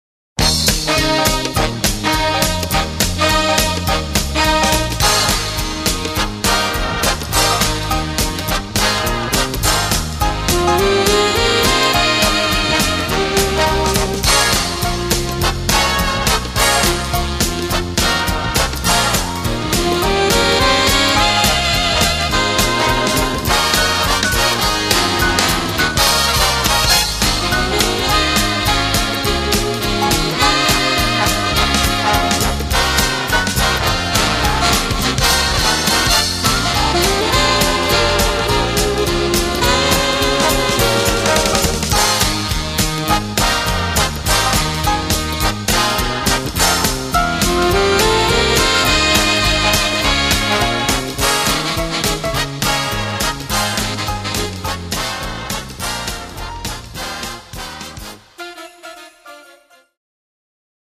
Gattung: Moderne Blasmusik
A4 Besetzung: Blasorchester Zu hören auf